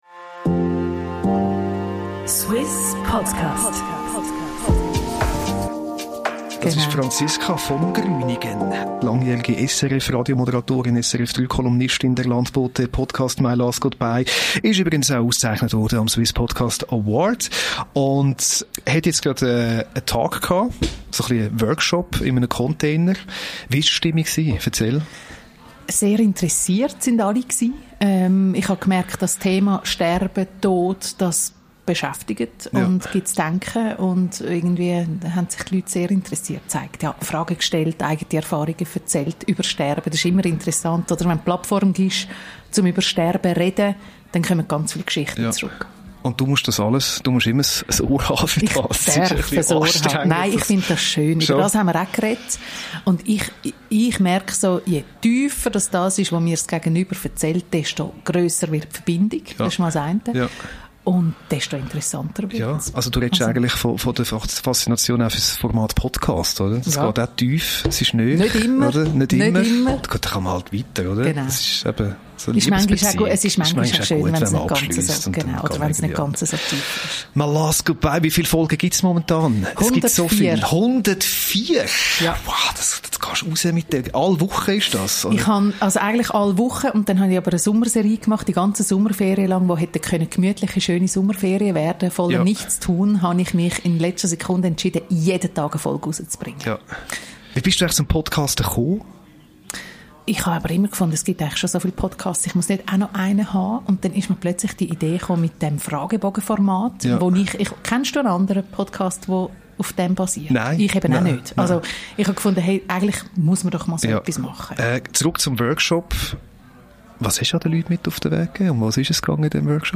In ihrem Workshop am Suisse Podcast Festival erklärte